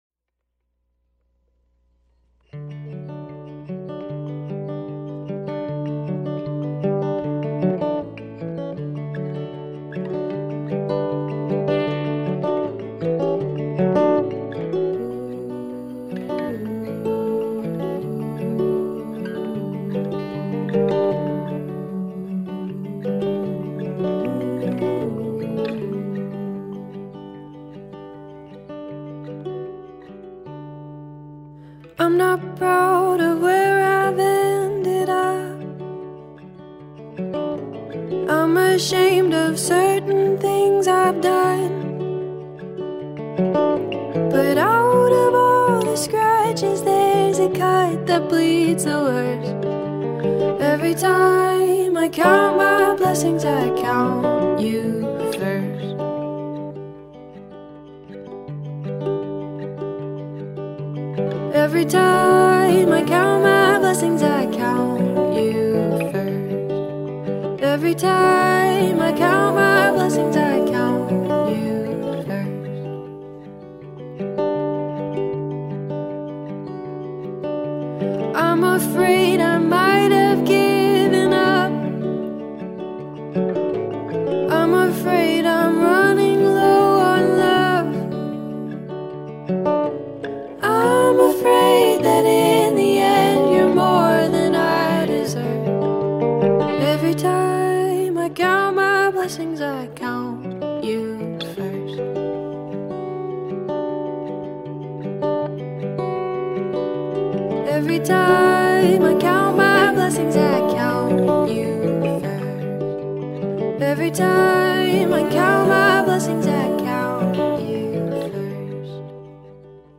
indie folk
pop gem